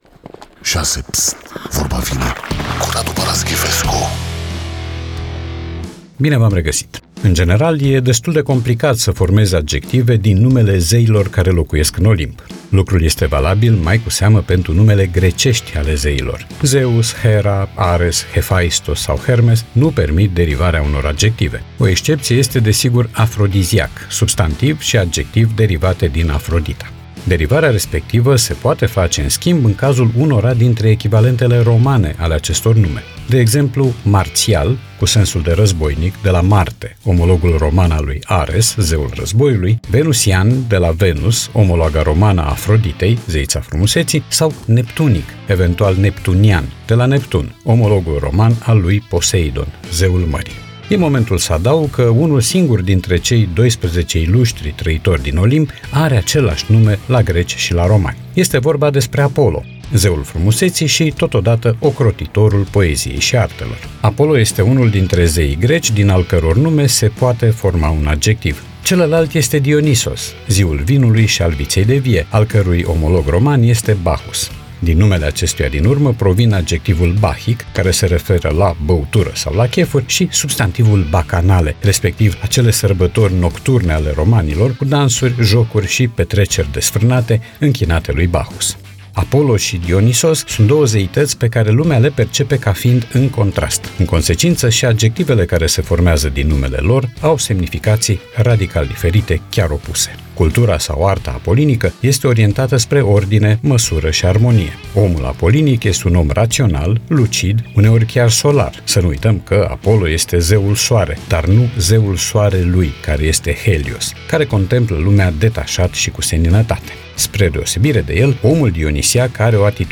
Podcast 4 aprilie 2025 Vezi podcast Vorba vine, cu Radu Paraschivescu Radu Paraschivescu iti prezinta "Vorba vine", la Rock FM.